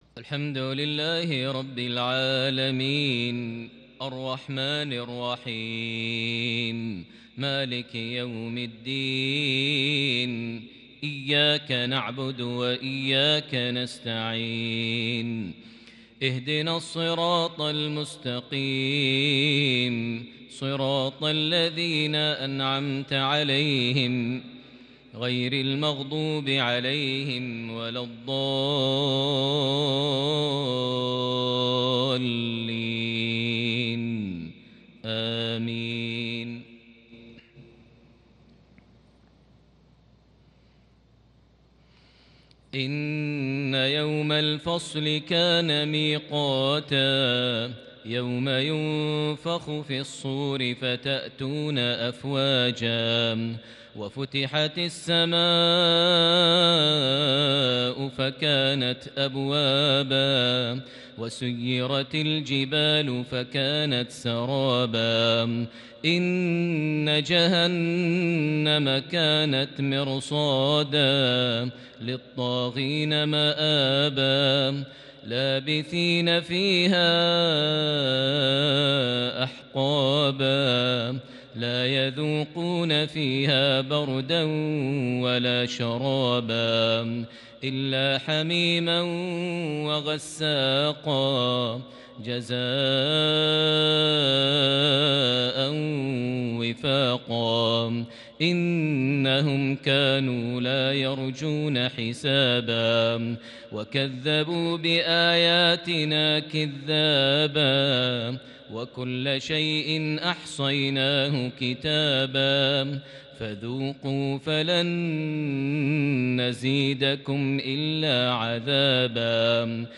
تلاوة لن تمل سماعها لخواتيم سورتي النبأ- النازعات عشاء 5 ذو القعدة 1441هـ > 1441 هـ > الفروض - تلاوات ماهر المعيقلي